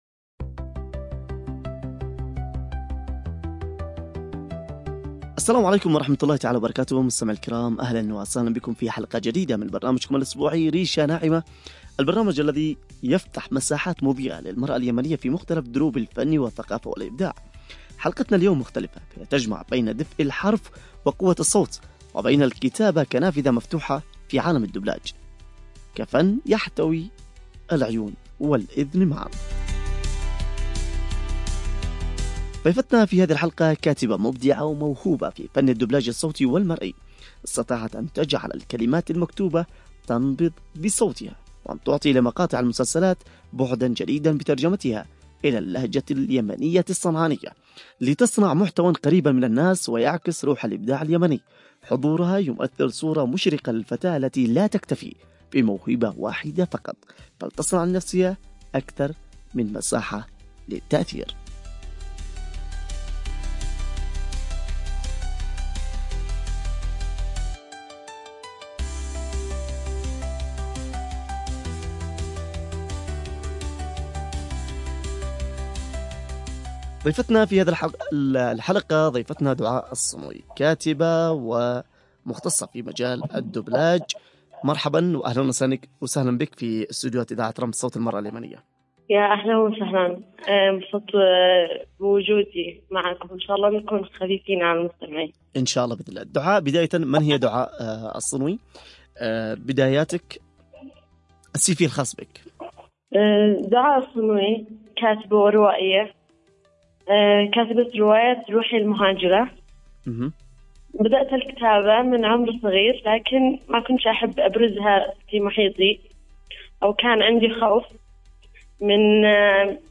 في حوار شيّق حول تجربة صناعة الدوبلاج باللهجة اليمنية
عبر أثير إذاعة رمز